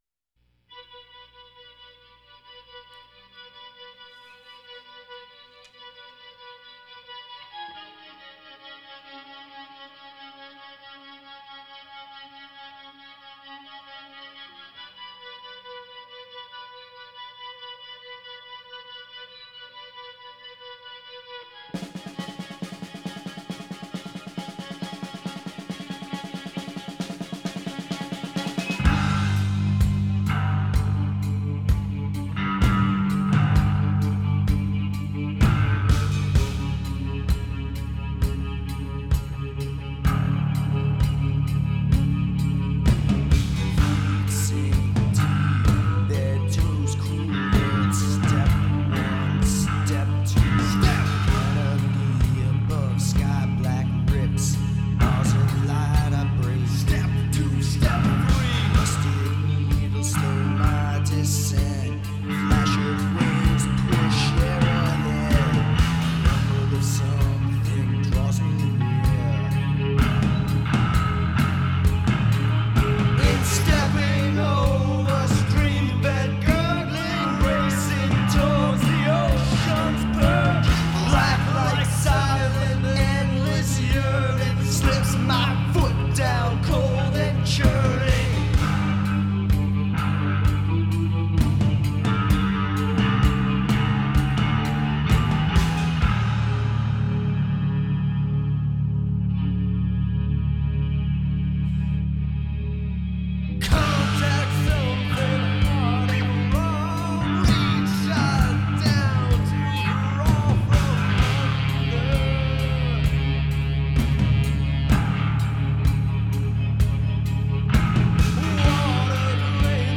High volume nasty noise at full industrial strength levels.